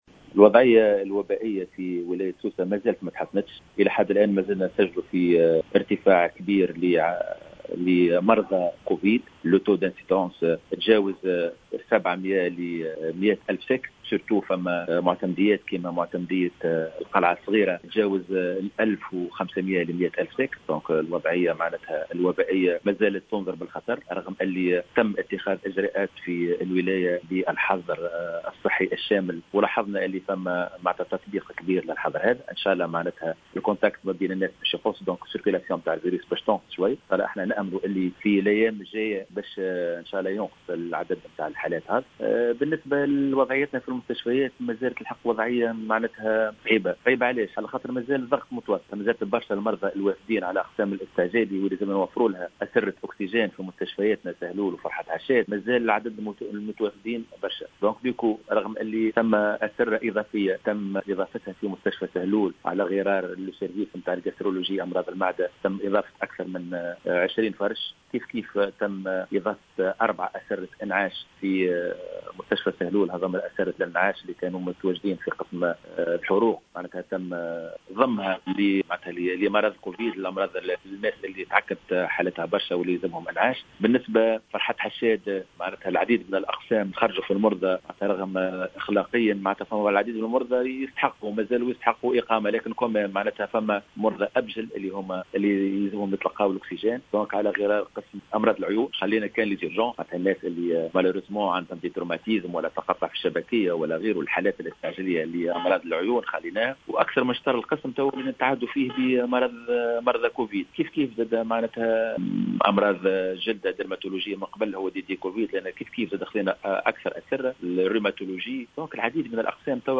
أكد المدير الجهوي للصحة بسوسة محمد الغضباني,في تصريح للجوهرة "اف ام" اليوم, أن الوضعية الوبائية في سوسة لم تتحسن بعد, وسط ارتفاع كبير للمصابين بكوفيد19.